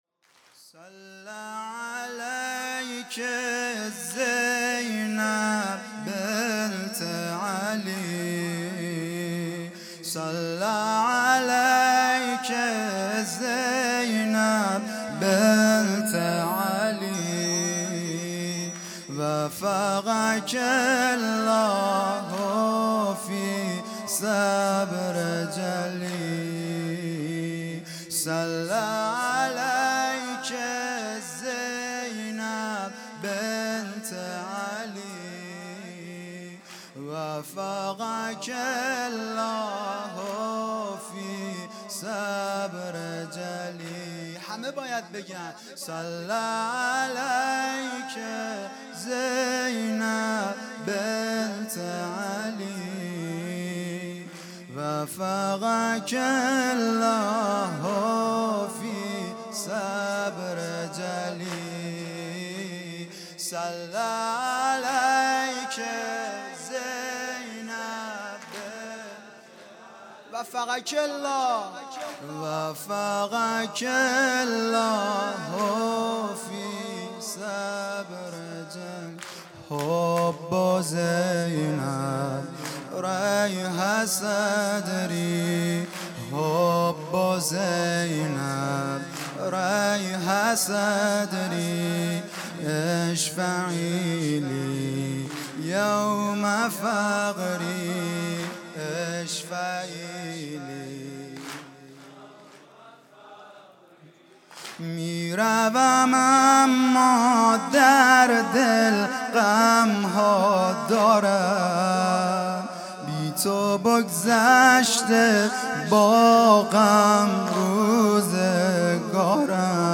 صل علیک زینب بنت علی|وفات حضرت زینب (س) ۹۶